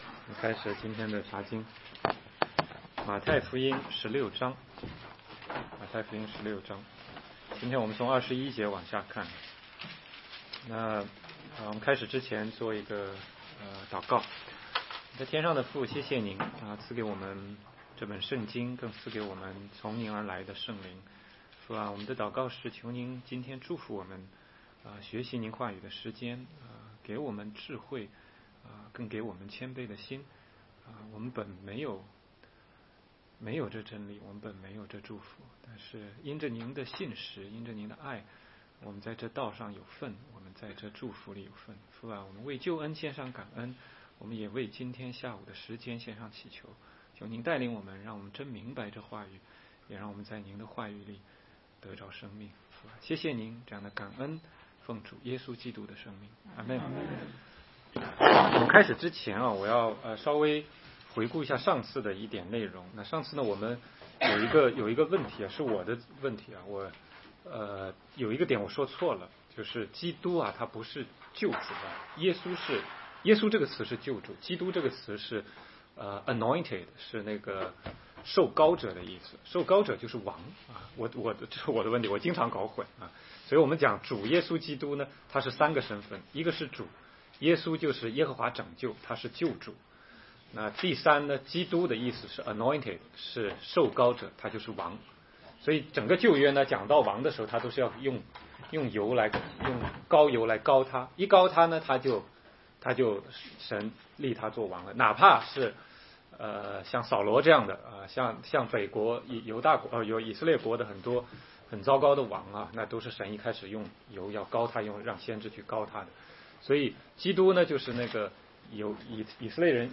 16街讲道录音 - 马太福音16章21-28节